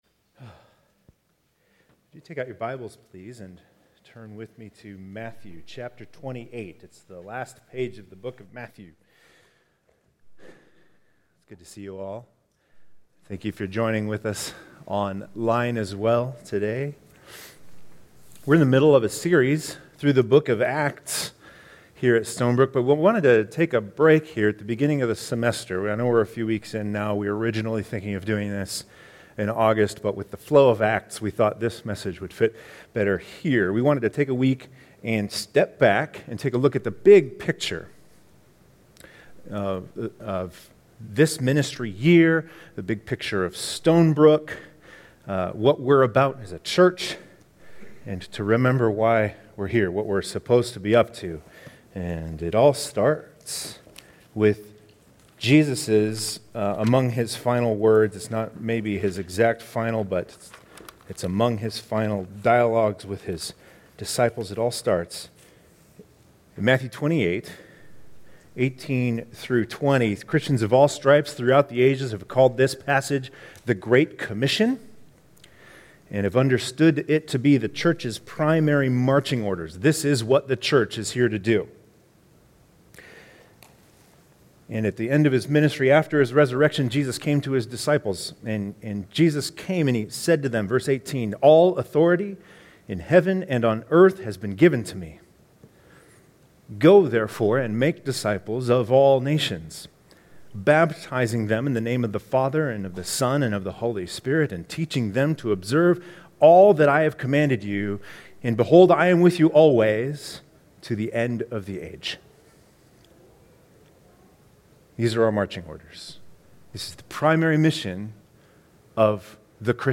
2020 Stay up to date with “ Stonebrook Church Sermons Podcast ”